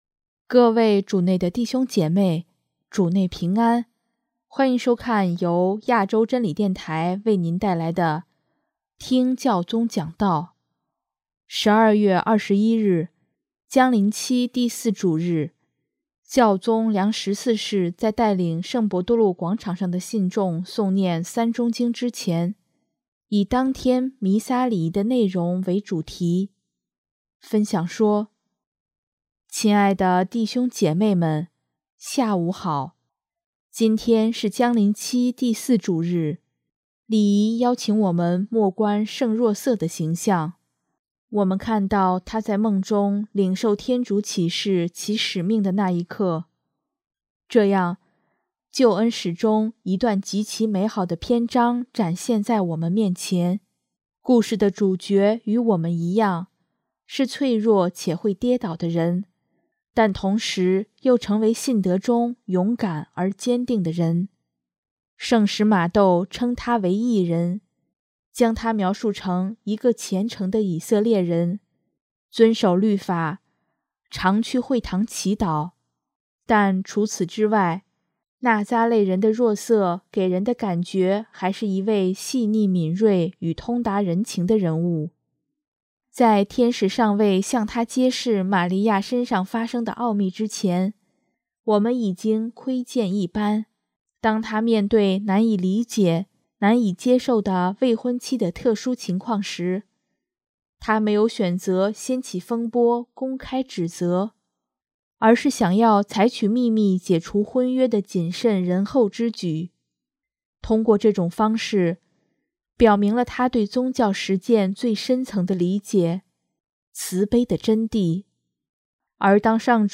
12月21日，将临期第四主日，教宗良十四世在带领圣伯多禄广场上的信众诵念《三钟经》之前，以当天弥撒礼仪的福音内容为主题，分享说：